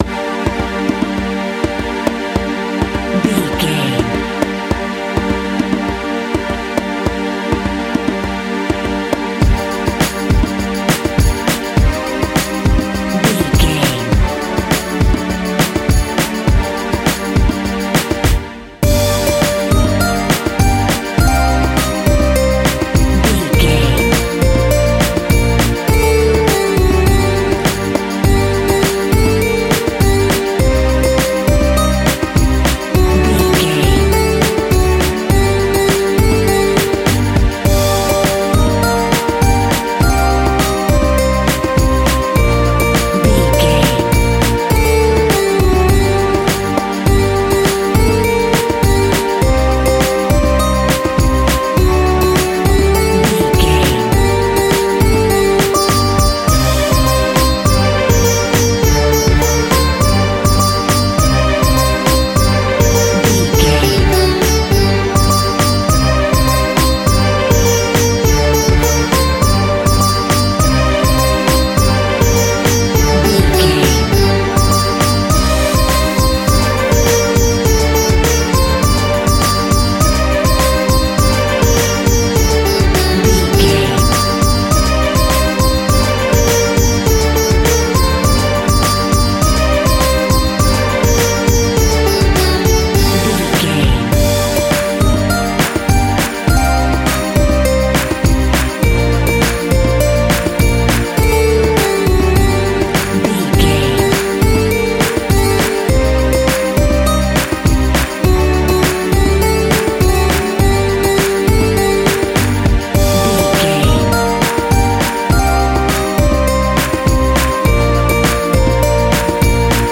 Aeolian/Minor
World Music
percussion